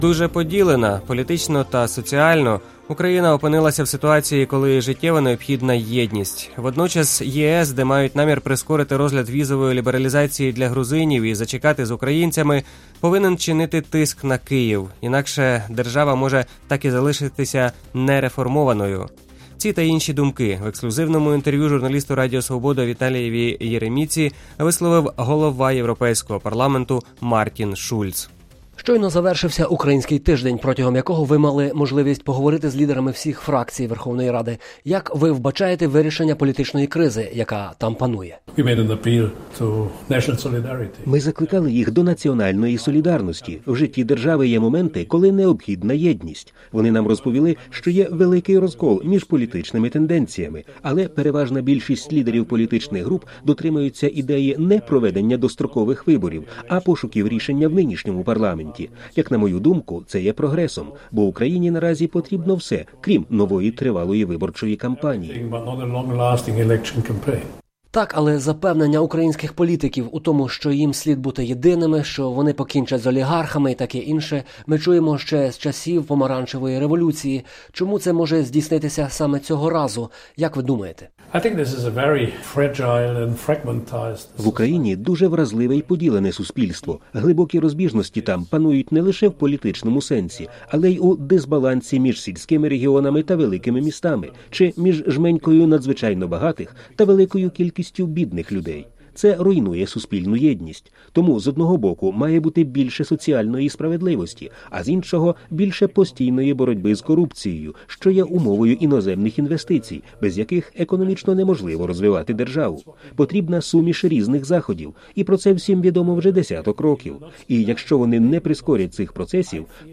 Ці та інші думки в ексклюзивному інтерв’ю Радіо Свобода висловив голова Європейського парламенту Мартін Шульц.